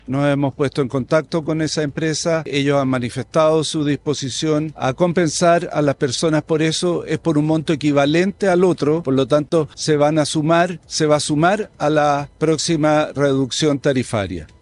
El biministro de Energía y Economía, Álvaro García, confirmó en un punto de prensa que la empresa TransELEC realizó un cobro adicional indebido en las tarifas eléctricas por una sobrevaloración de su capital, lo que generó un nuevo error en los montos cobrados a los usuarios.